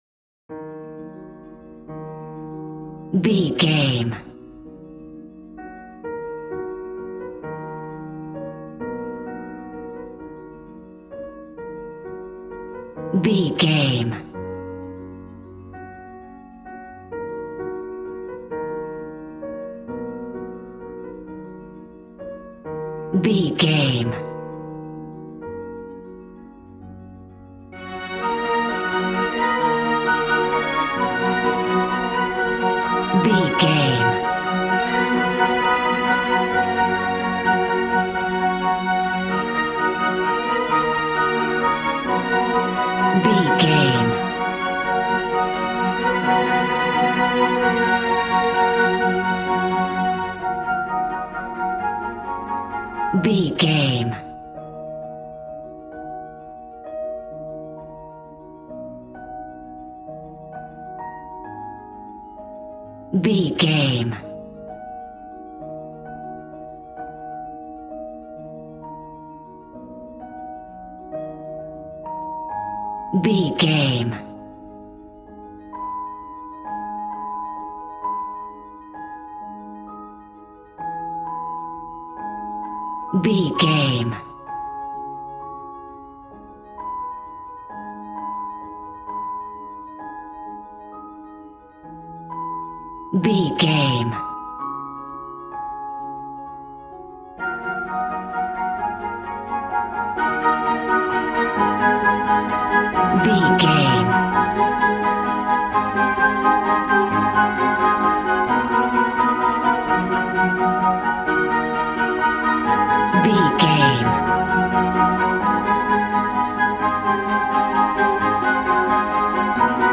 Regal and romantic, a classy piece of classical music.
Ionian/Major
G♭
regal
strings
violin